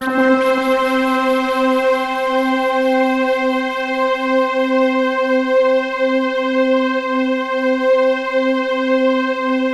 Index of /90_sSampleCDs/USB Soundscan vol.13 - Ethereal Atmosphere [AKAI] 1CD/Partition C/06-POLYSYNTH